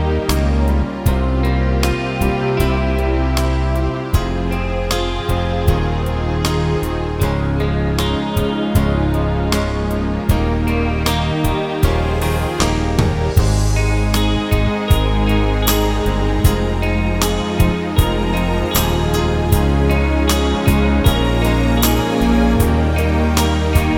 no Backing Vocals Soul / Motown 4:07 Buy £1.50